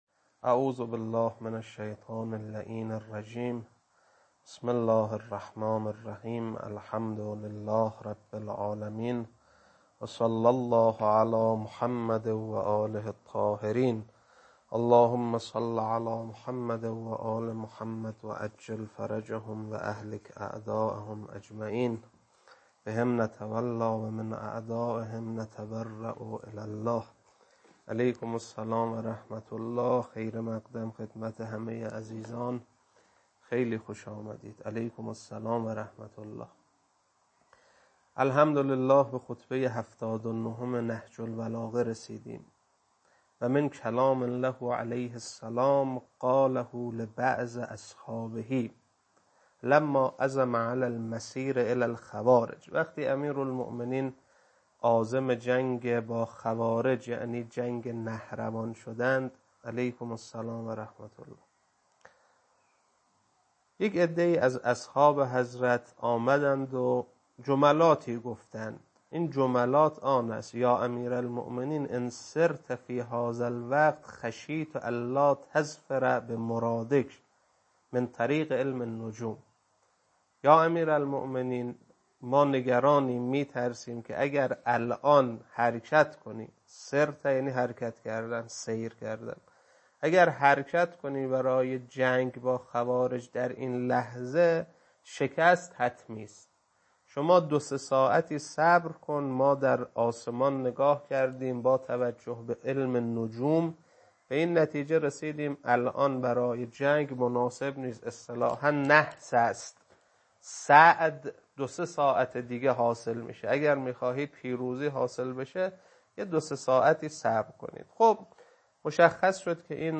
خطبه 79.mp3